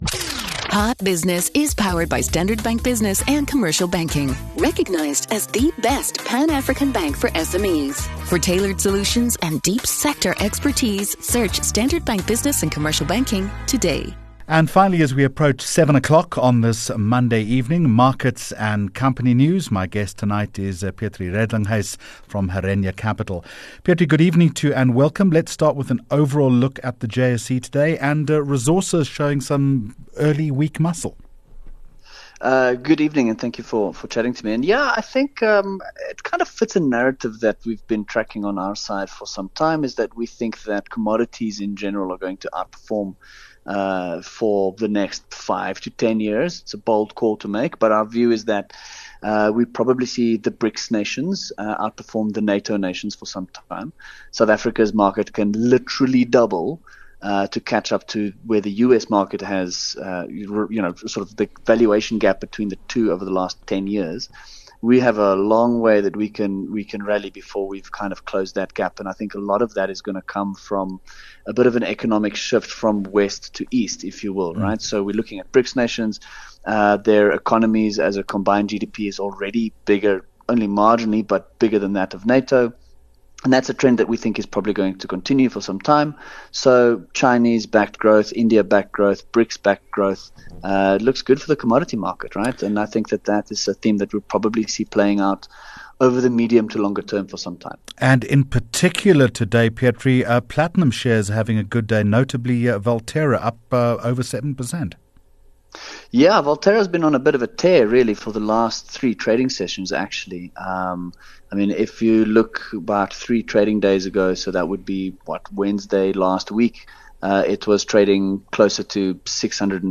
9 Jun Hot Business Interview